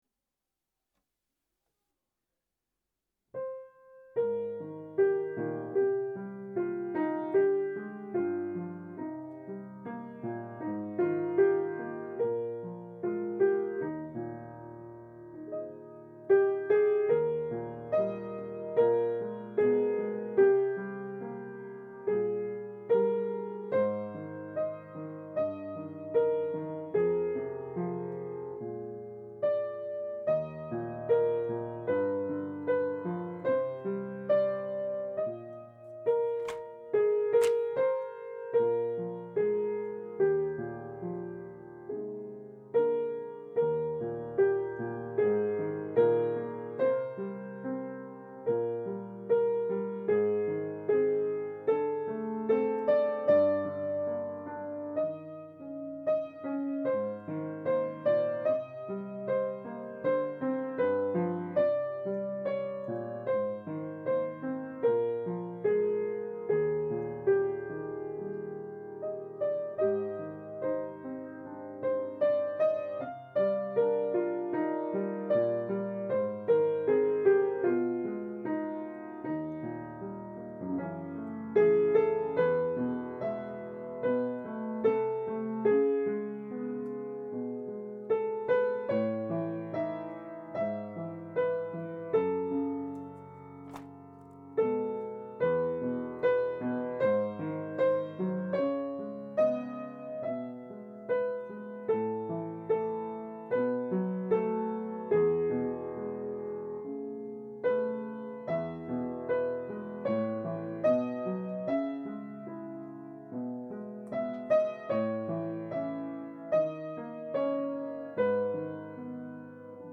673  〈ここも神の〉テナーパート練習